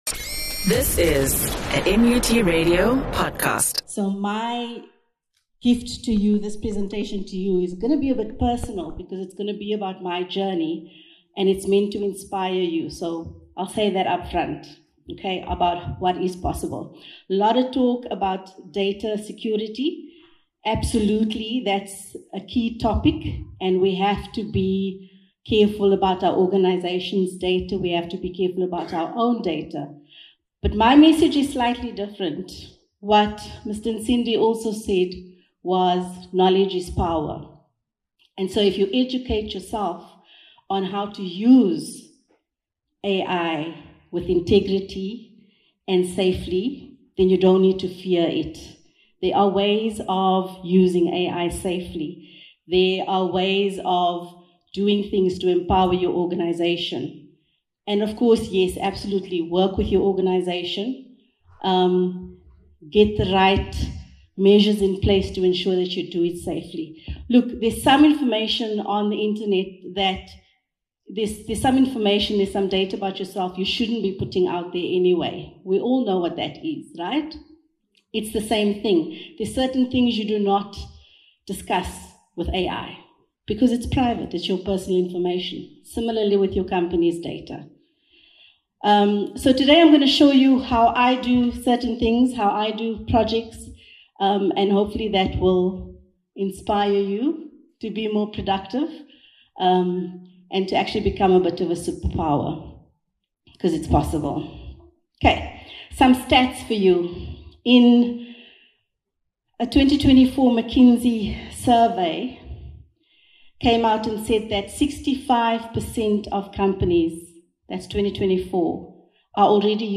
presentation